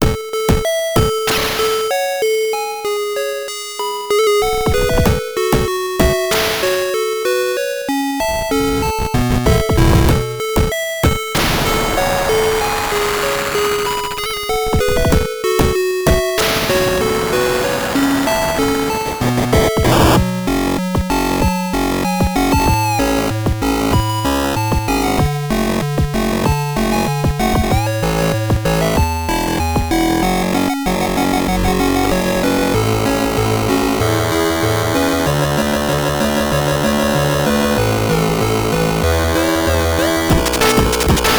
a tune i wrote on guitar and transcribed over to lsdj